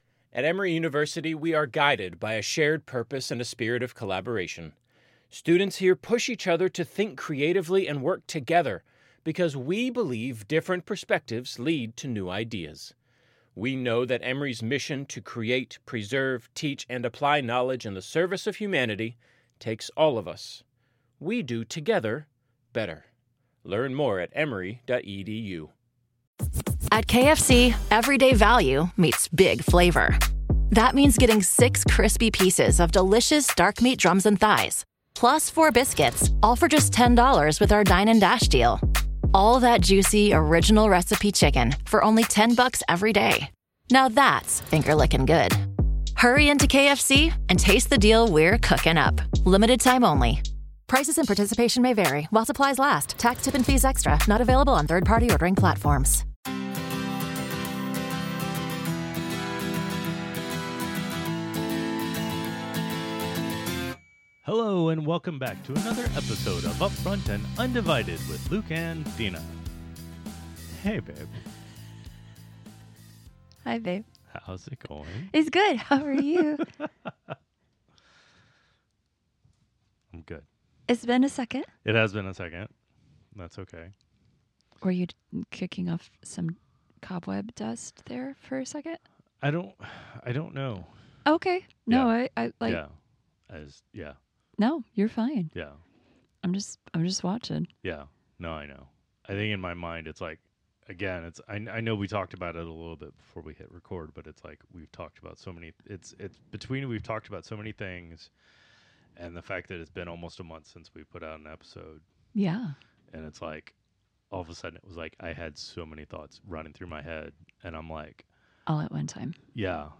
Real conversations with a real couple. In our podcast we will talk about and take on a variety of topics including: - Relationships/Marriage - Courting/Dating - Honor - Social Situations - Life - God and Christianity - Sex - Children - and any other random thing we might think of.